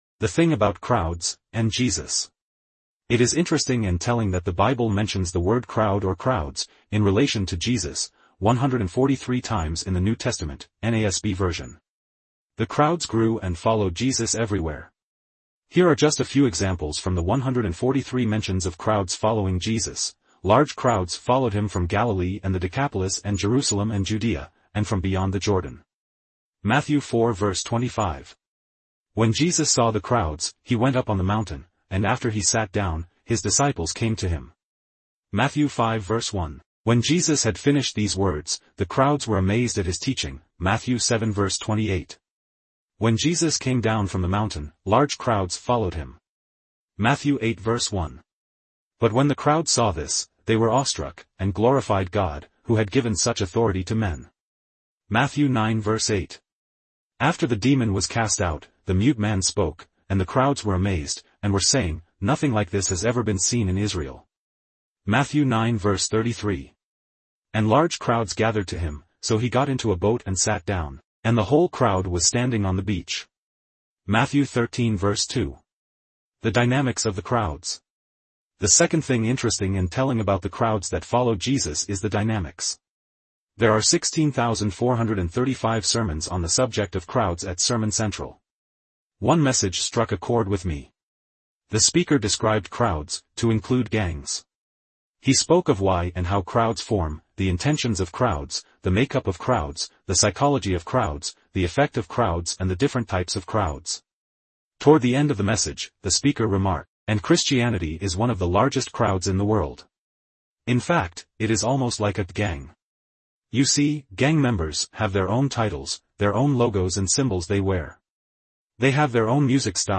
ttsmaker_file_2024_2_7_14_31_23.mp3